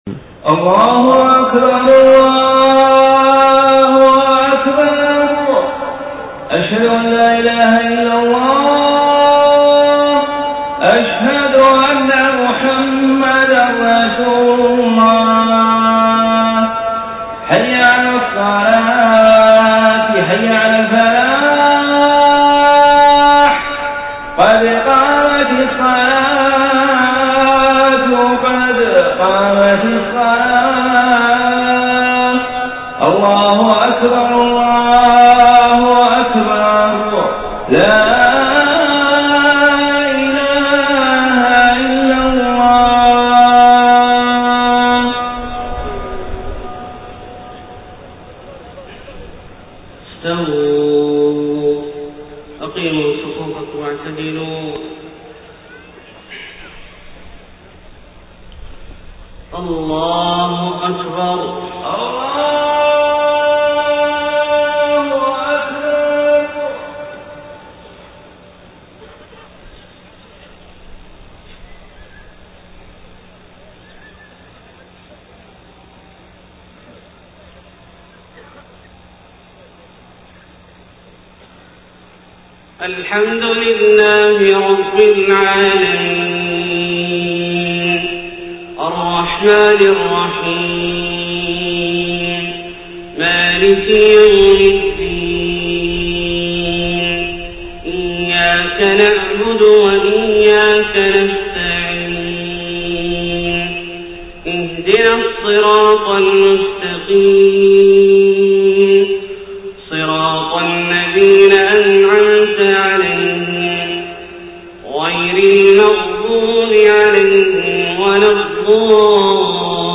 صلاة الفجر 14 ربيع الأول 1431هـ من سورة النور {27-34} > 1431 🕋 > الفروض - تلاوات الحرمين